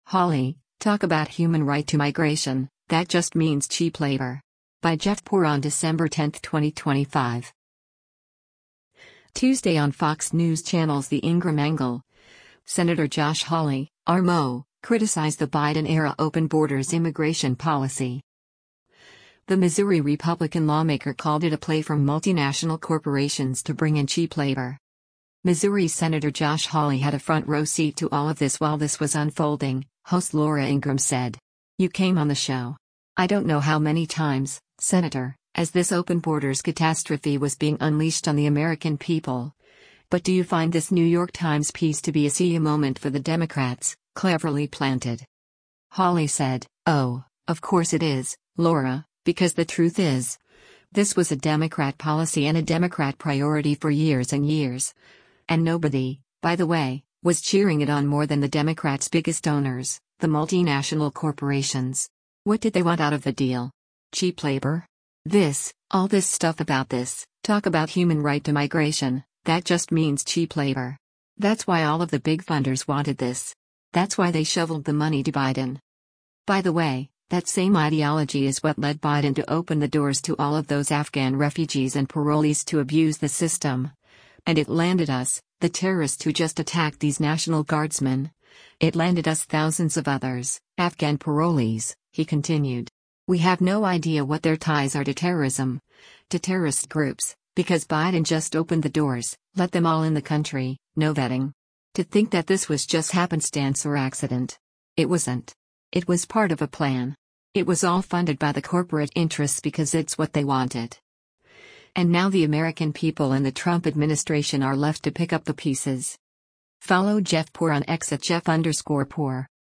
Tuesday on Fox News Channel’s “The Ingraham Angle,” Sen. Josh Hawley (R-MO) criticized the Biden-era open borders immigration policy.